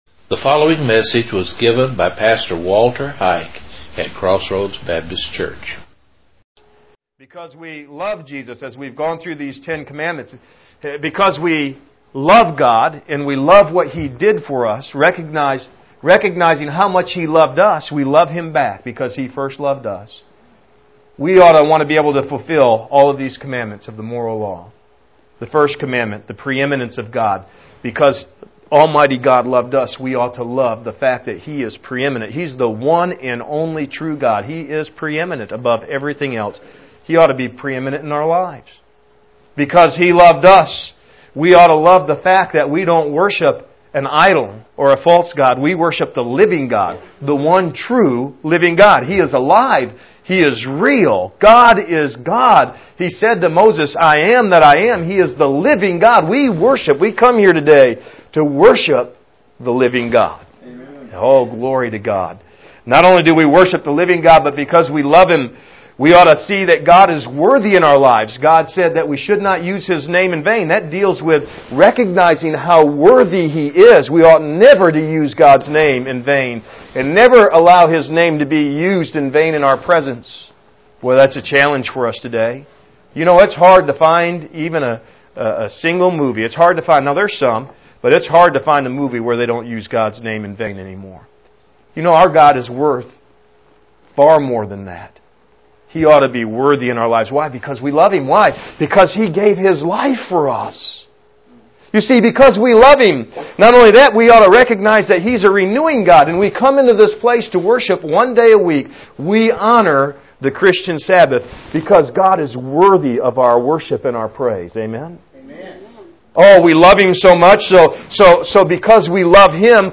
Trust And Integrity Outline and Audio Sermon